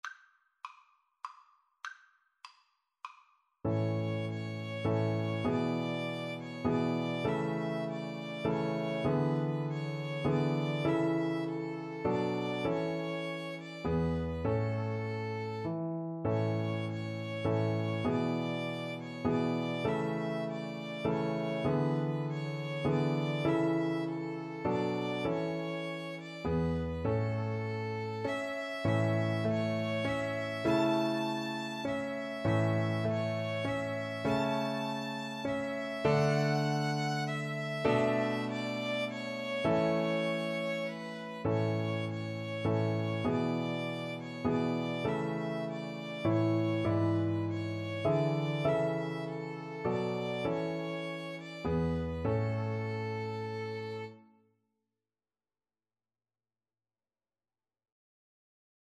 A major (Sounding Pitch) (View more A major Music for Violin Duet )
3/4 (View more 3/4 Music)
Traditional (View more Traditional Violin Duet Music)